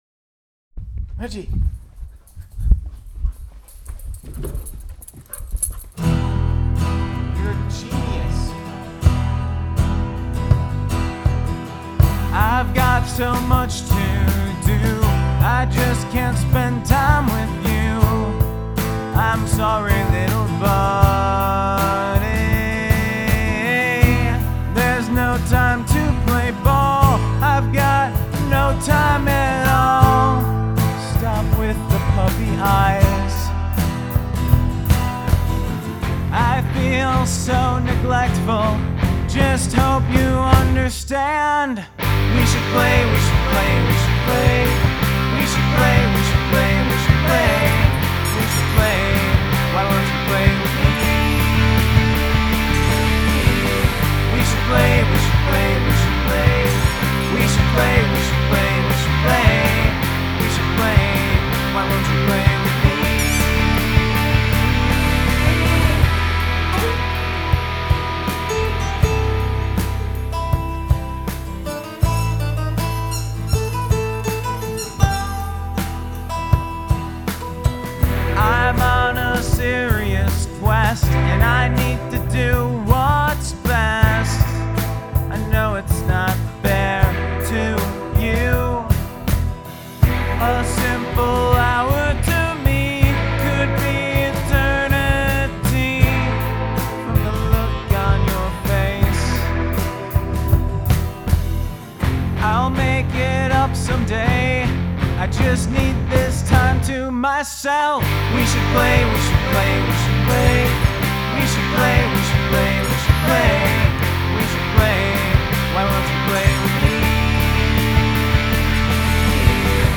Have a guest play a household item on the track
Heir to the Pringles Fortune on the squeaker toy.
this is cute and catchy.
The squeaky toy cracked me up.